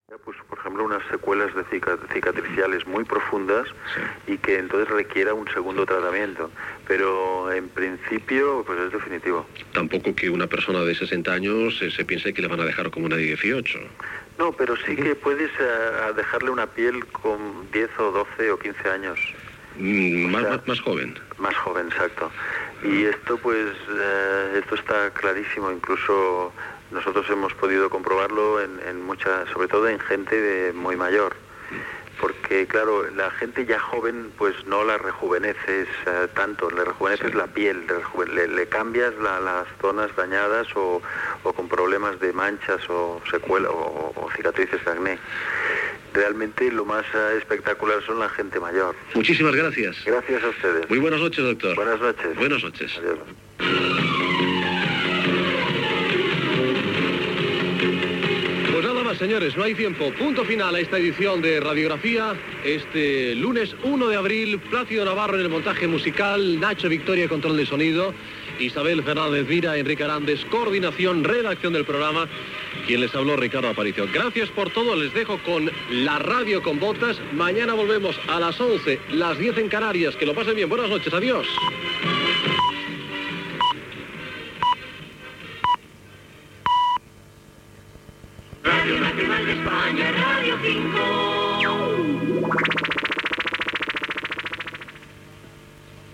Final de l'entrevista a un dermatòleg, data comiat, equip del programa i indicatiu de l'emissora
Divulgació